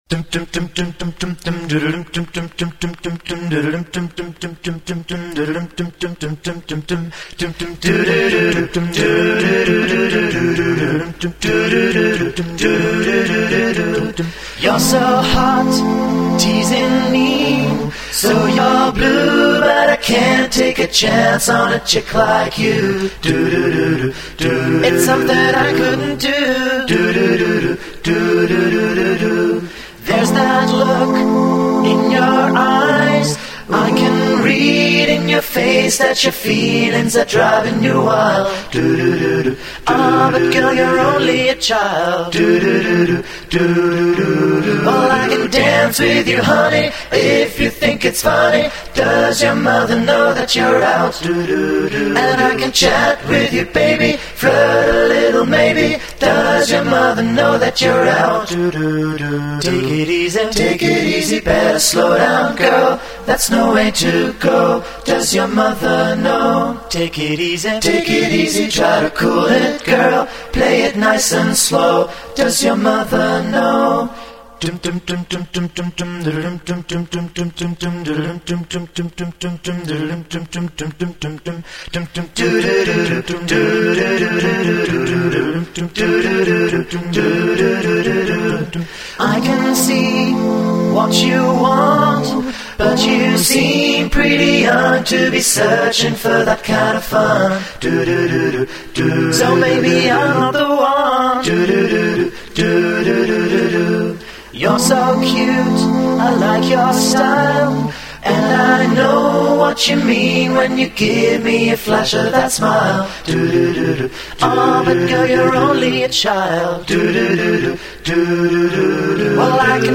aufgenommen 2005  4-stimmig   6:47 min   6,21 mb